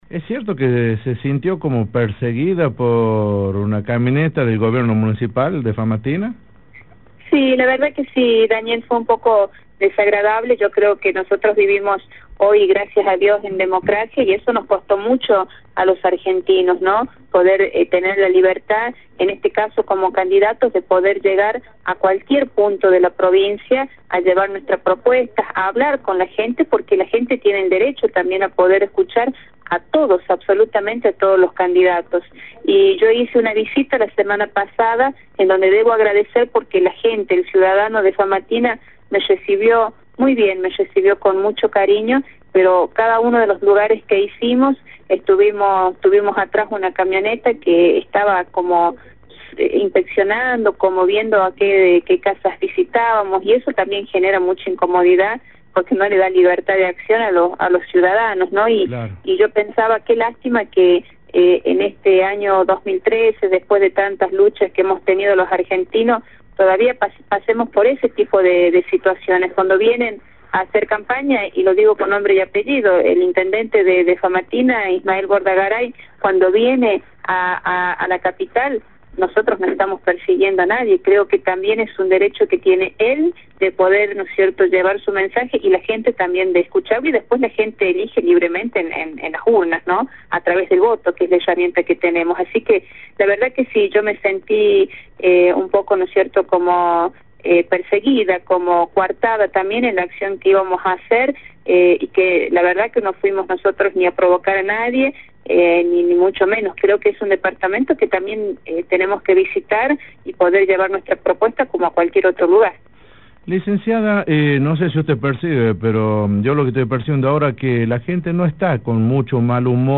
La entrevista con Teresita Madera